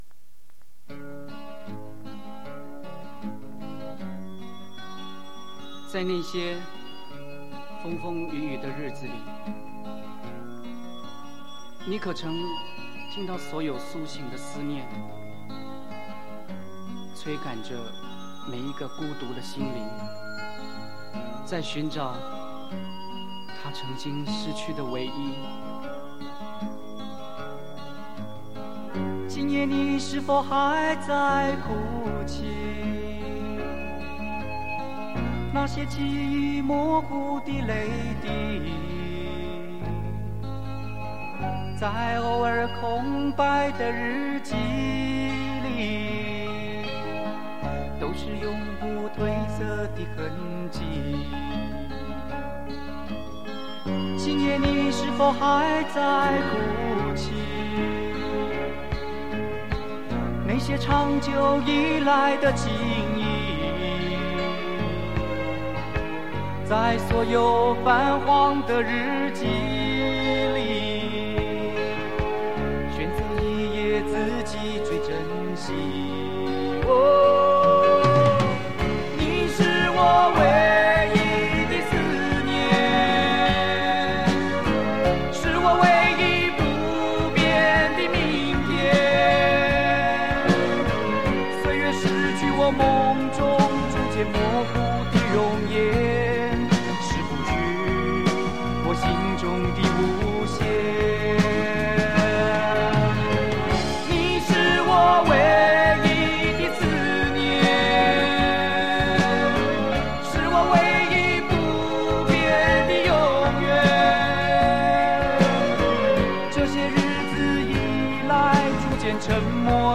磁带数字化